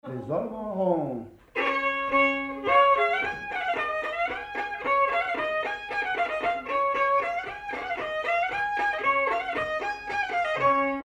Mazurka partie 3
danse : mazurka
circonstance : bal, dancerie
Pièce musicale inédite